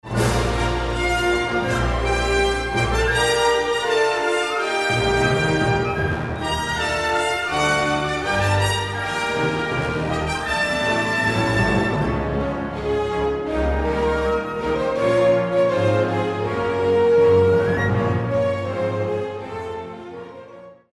OST
Музыка из фильма